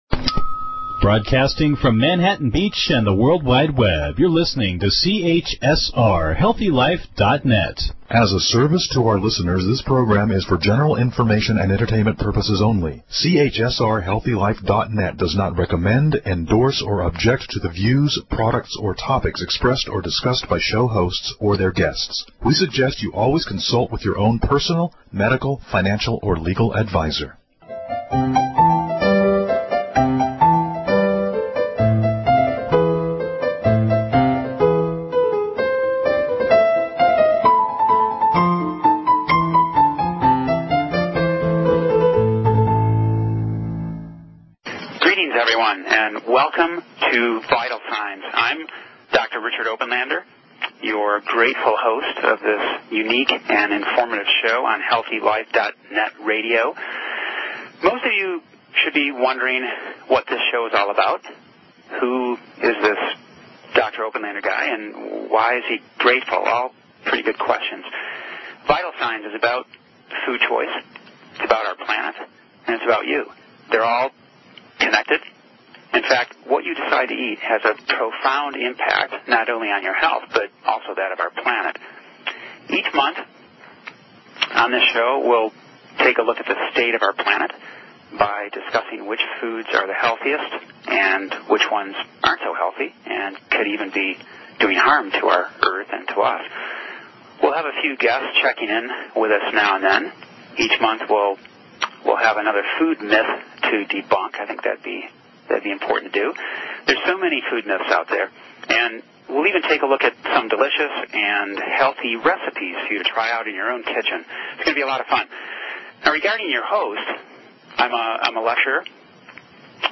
iEat Green Radio Interview